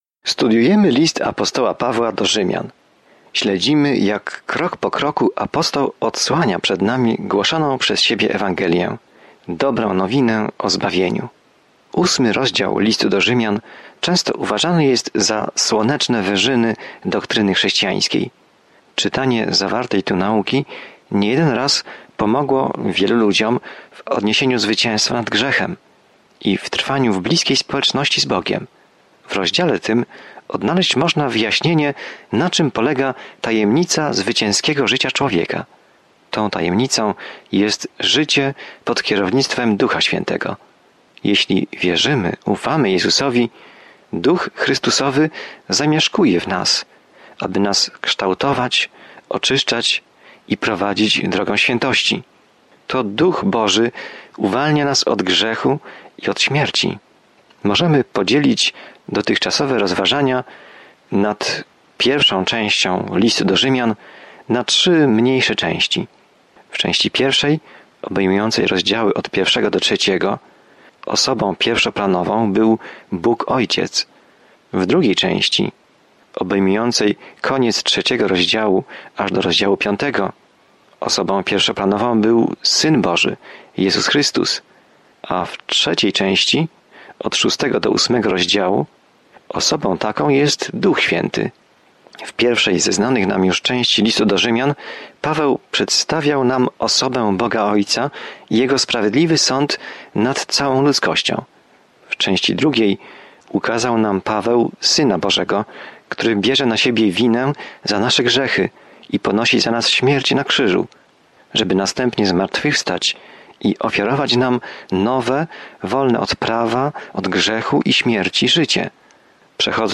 Pismo Święte Rzymian 8:1-14 Dzień 16 Rozpocznij ten plan Dzień 18 O tym planie List do Rzymian odpowiada na pytanie: „Jaka jest dobra nowina?” I jak każdy może uwierzyć, zostać zbawiony, uwolniony od śmierci i wzrastać w wierze. Codzienna podróż przez List do Rzymian, słuchanie studium audio i czytanie wybranych wersetów słowa Bożego.